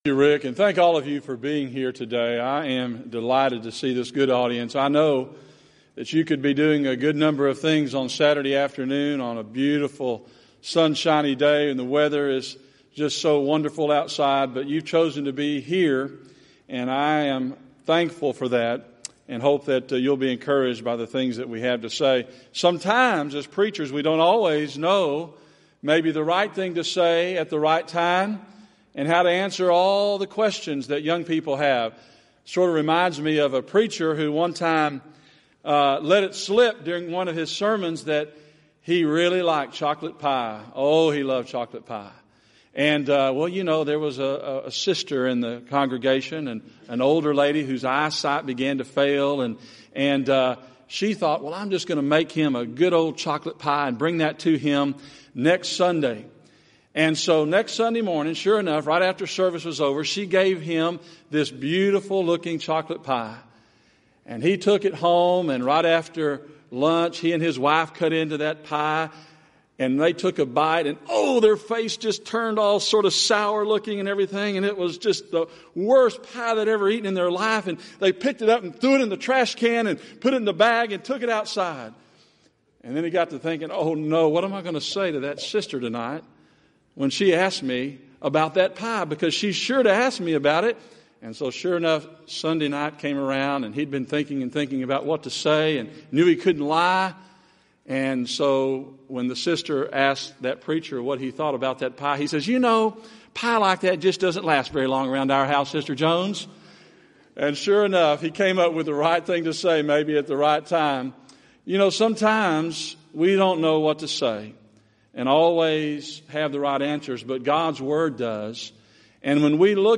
Event: 29th Annual Southwest Lectures
lecture